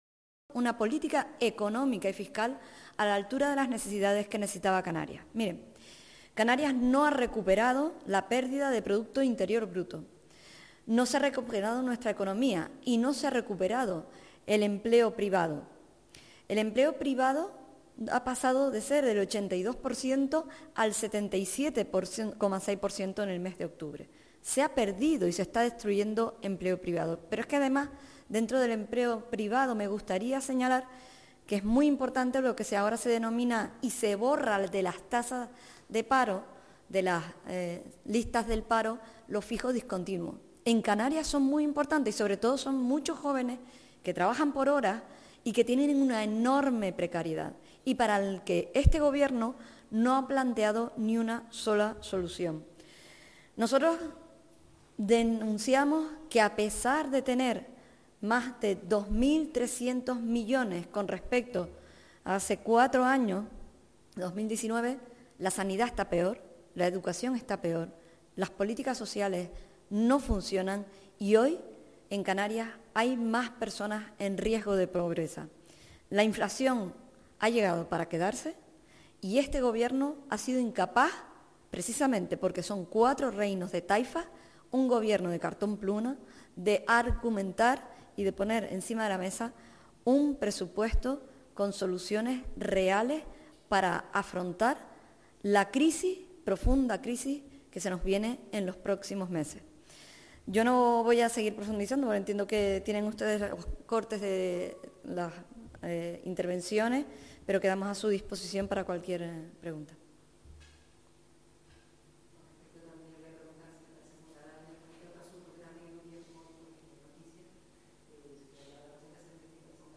Rueda de prensa del GP Nacionalista Canario sobre valoración de los Presupuestos Generales de la Comunidad Autónoma de Canarias 2023 - 14:30